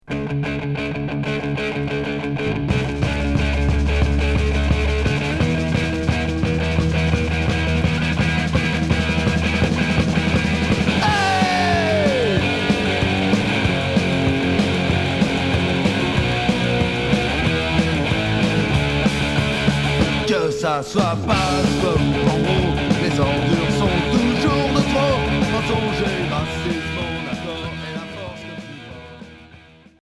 Street punk Quatrième 45t retour à l'accueil